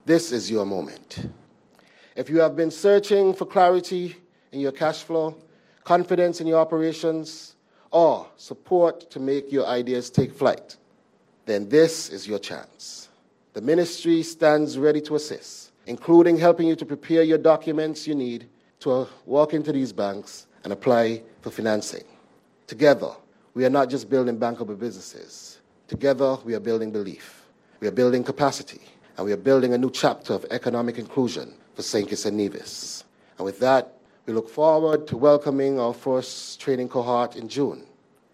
Also, Minister of Small Business, Entrepreneurship, et. al., the Hon Samal Duggins, shared this message with local entrepreneurs:
Minister Samal Duggins.